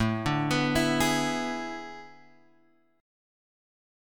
A Major 7th Suspended 2nd Suspended 4th